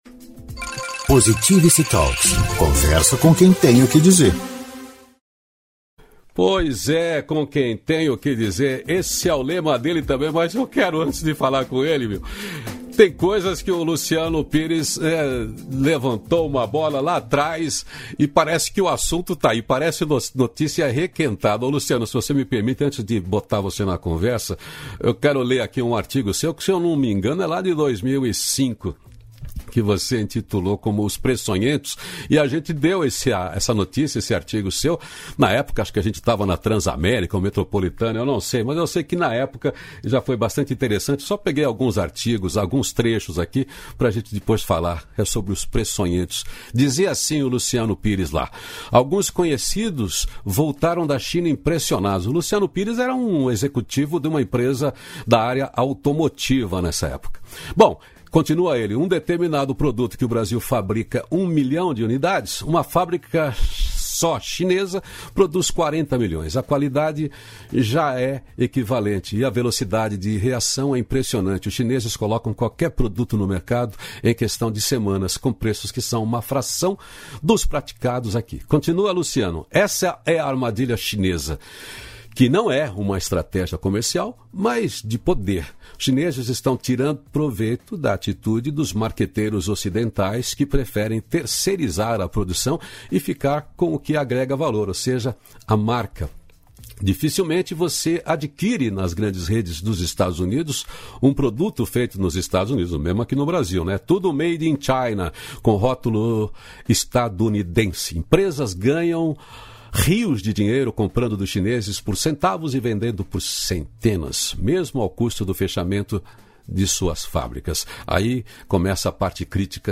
233-feliz-dia-novo-entrevista.mp3